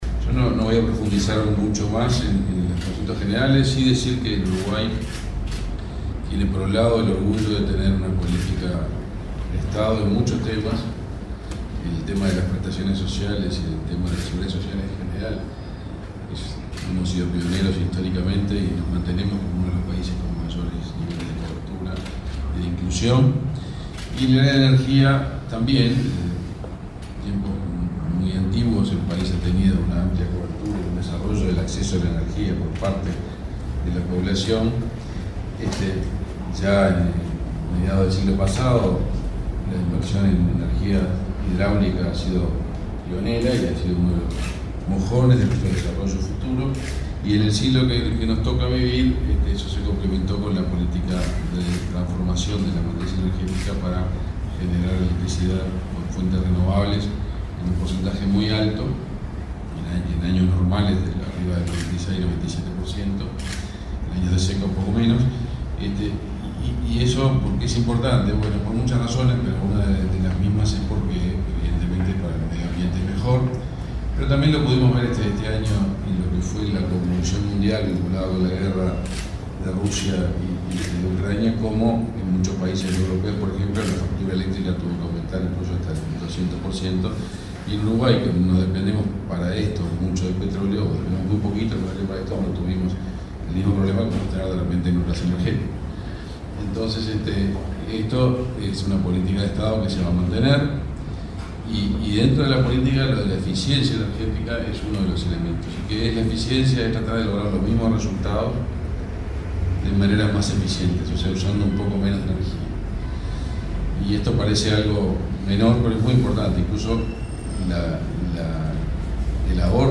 Palabras del ministro de Industria, Omar Paganini